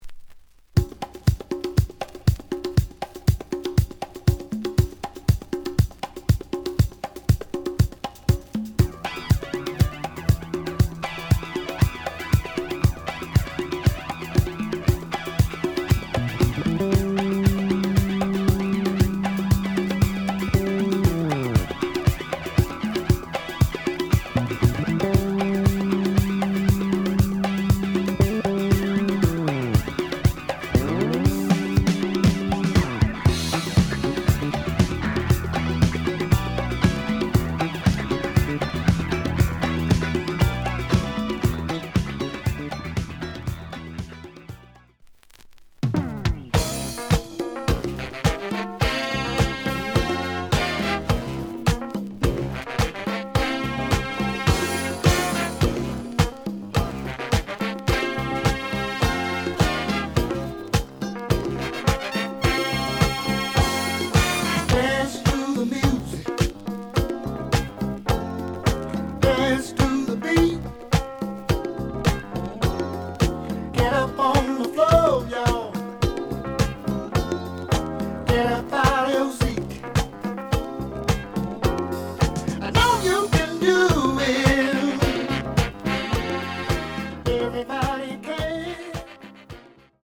The audio sample is recorded from the actual item.
●Format: LP
●Genre: Soul, 70's Soul